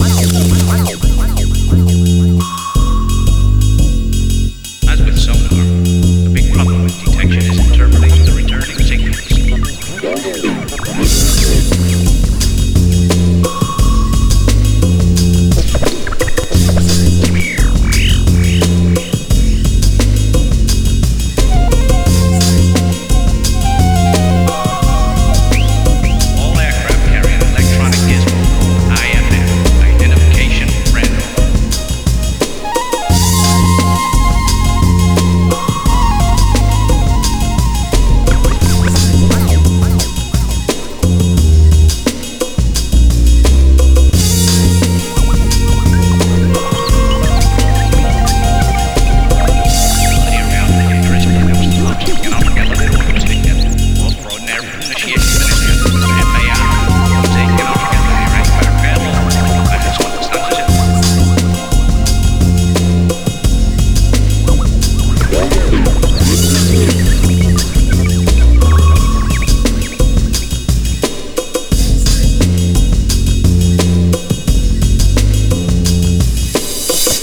As implied, this is mono ADPCM at 44.1KHz 16-bit.